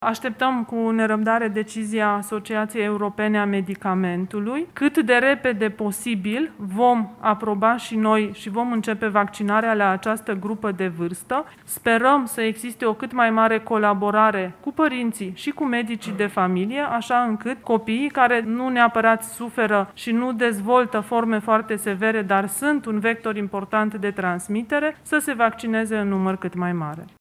Ministrul Sănătăţii, Ioana Mihăilă: